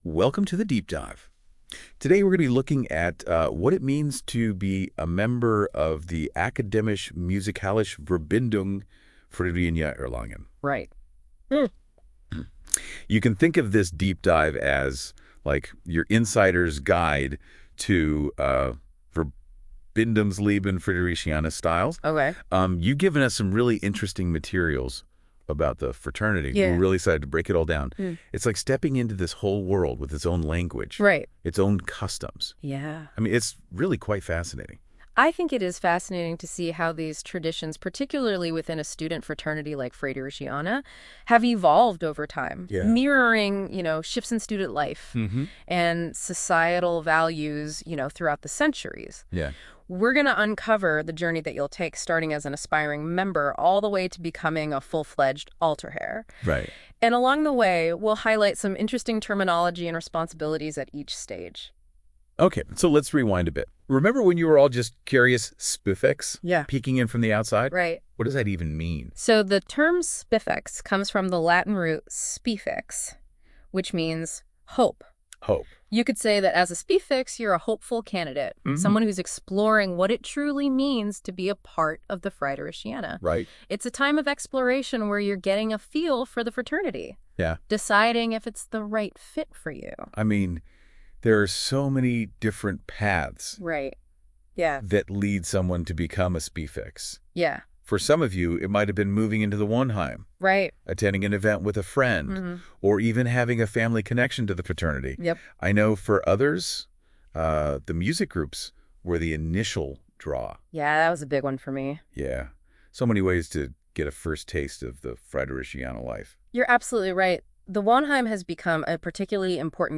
KI-Podcast zu einer Fuxenstunde der Studentenverbindung AMV Fridericiana Erlangen
KI-Podcast aus Fuxenstunde